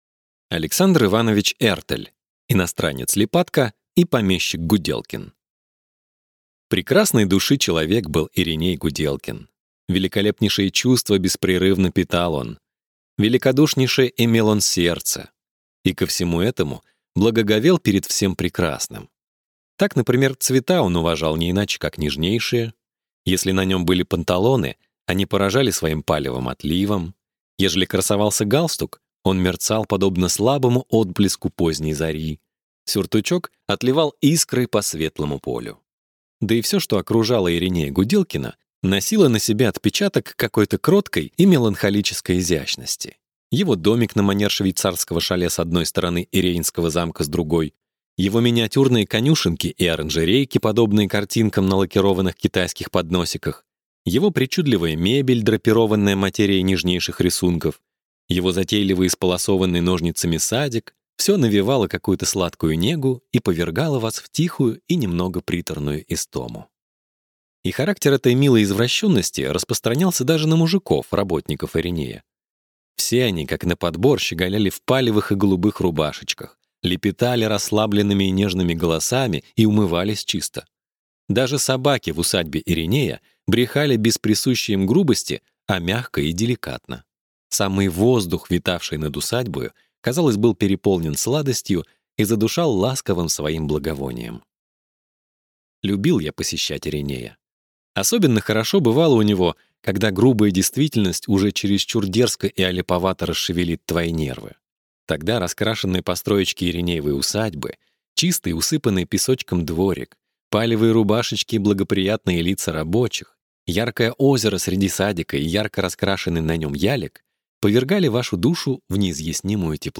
Аудиокнига Иностранец Липатка и помещик Гуделкин | Библиотека аудиокниг